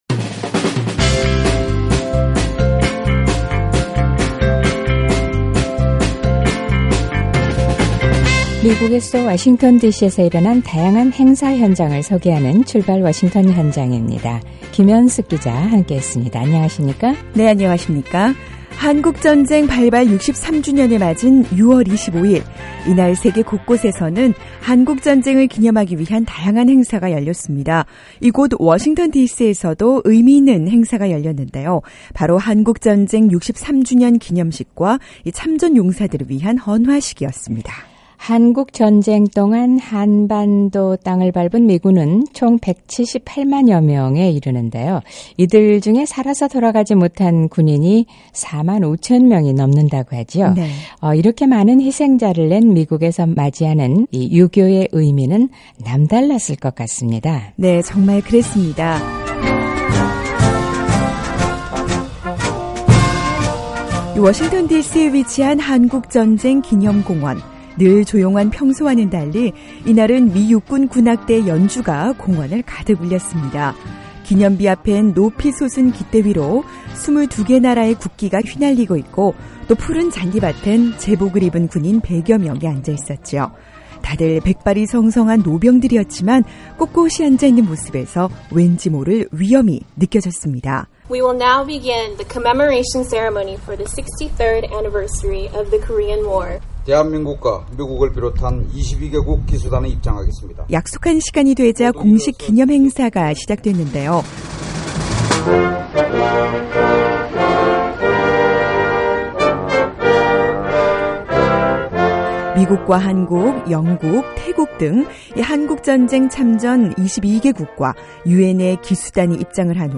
한국전쟁 63주년을 맞은 6월 25일, 워싱턴DC에서도 참전용사들의 희생을 기념하는 한국전쟁 기념식과 헌화식이 열렸습니다. 이제는 노병이 된 참전용사들, 행사를 돕기 위해 자원봉사를 한 10대 학생들, 참전국을 대표해서 온 외국인들, 우연히 왔다가 한국 전쟁 기념식을 보게 된 관광객들. 6월 25일, 워싱턴디씨의 한국전쟁기념공원을 찾은 다양한 사람들의 이야기와 그들이 생각하는 한국 전쟁에 대해 들어봅니다.